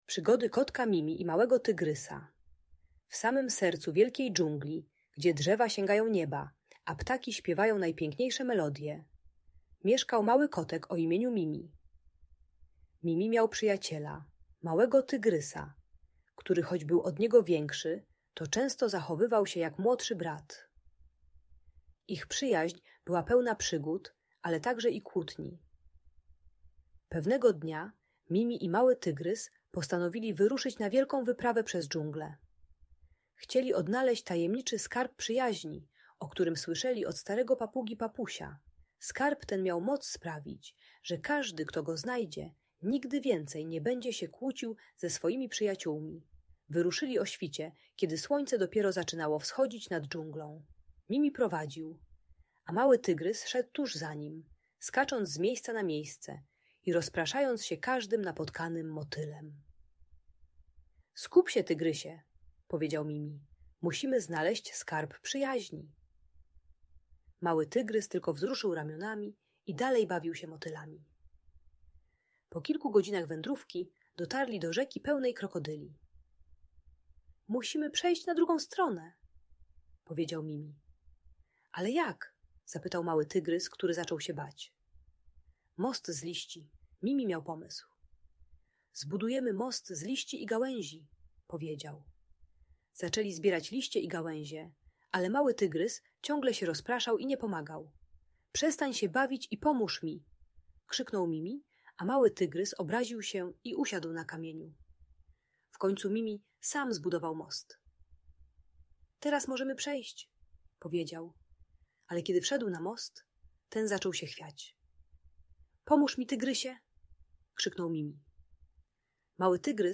Przygody Kotka Mimi i Małego Tygrysa - Audiobajka